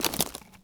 wood_tree_branch_move_09.wav